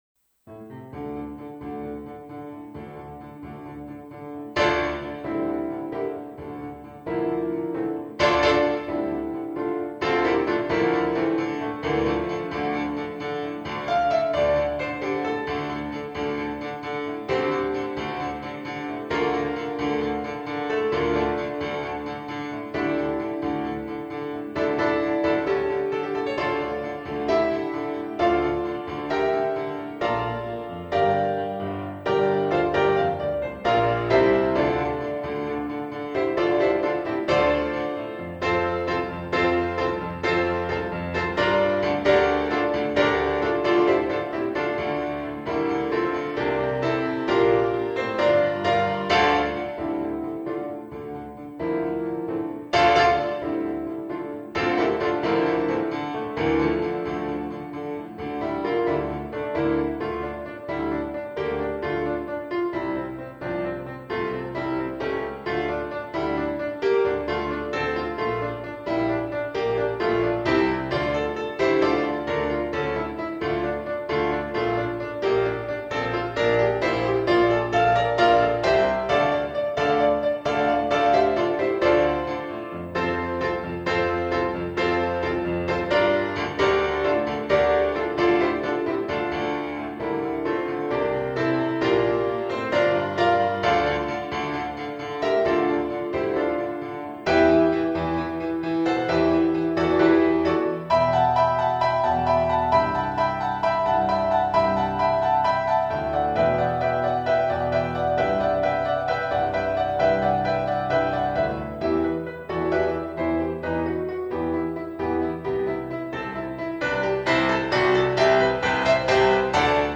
Ghost Riders In The Sky Backing | Ipswich Hospital Community Choir